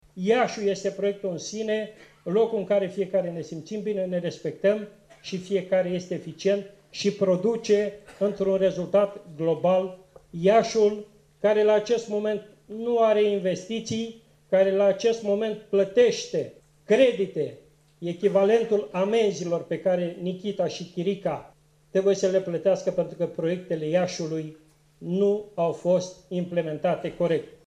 Iaşi: Traian Băsescu, prezent la evenimentul de lansare a candidaţilor PMP la alegerile locale